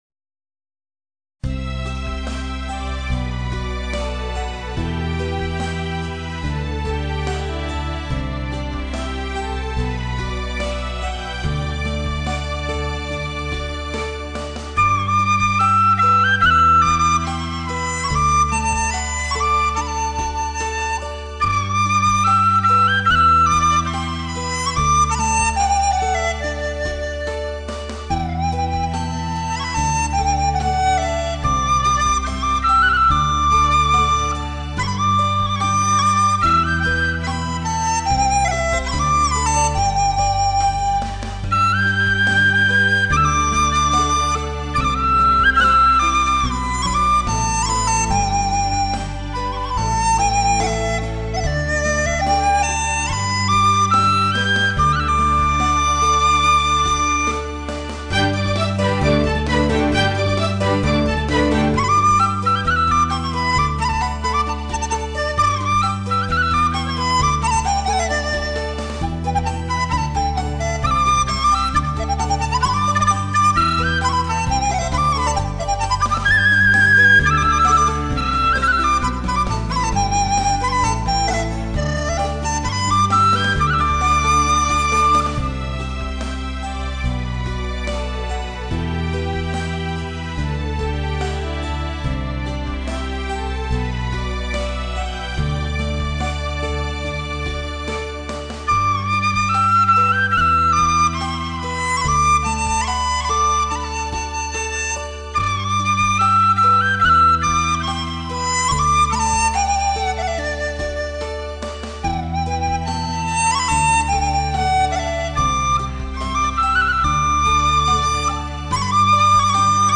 笛子